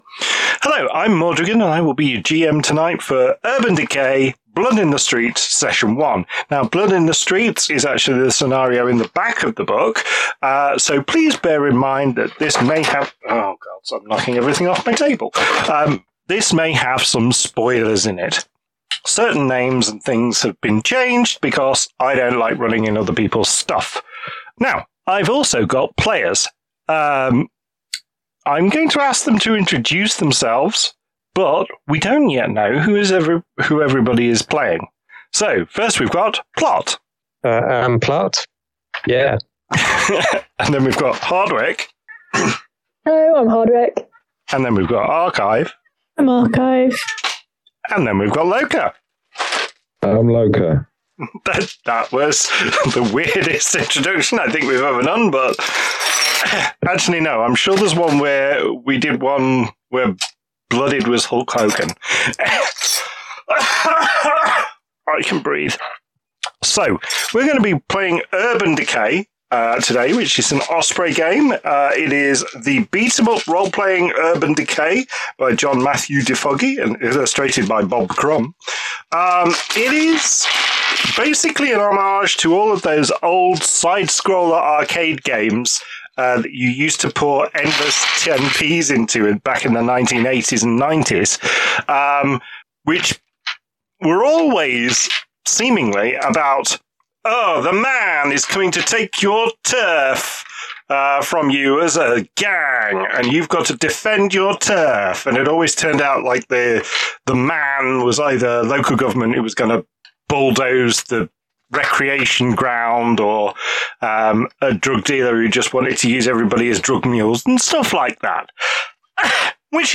This entry was posted on 12 April 2026 at 09:30 and is filed under Actual Play , Podcasts , Sixth 100 with tags Clash System , Osprey , Osprey Games , Urban Decay .